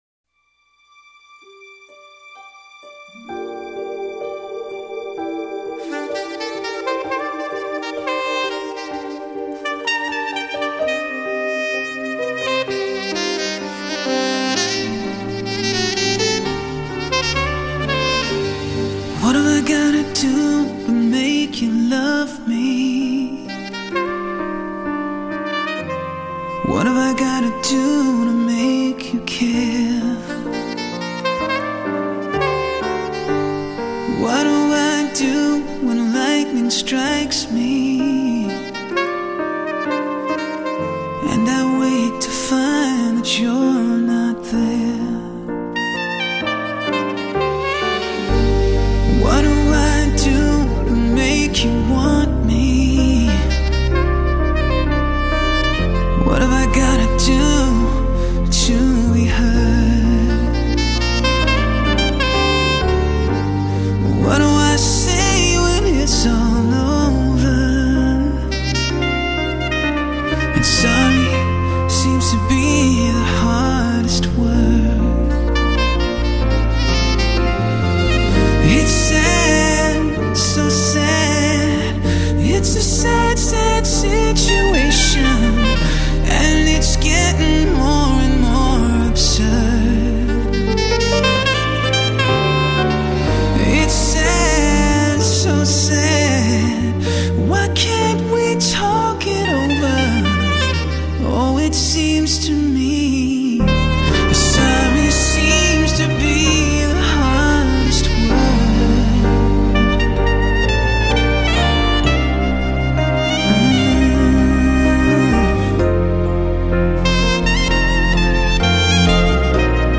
日本JVC特别低音处理，试听时注意调节BASS（低音）！
极致的发烧录音24Bit高解析录音